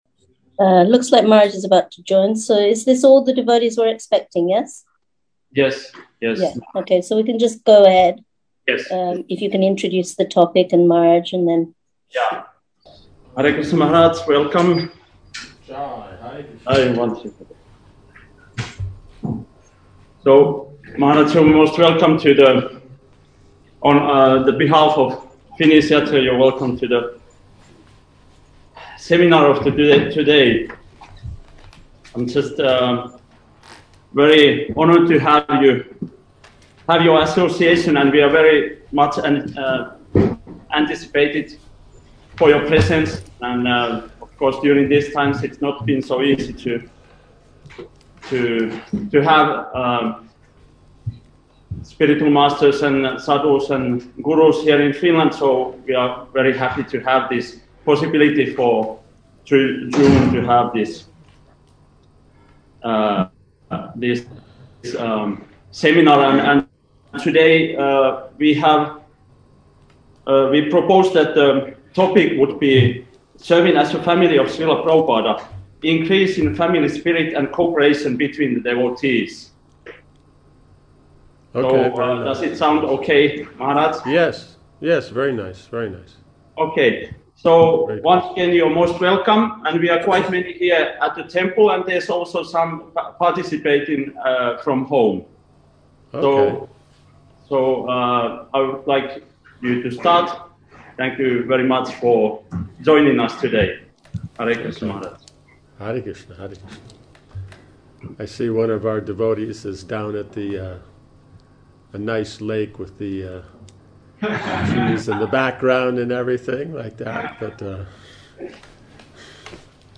Cooperation of Devotees Seminar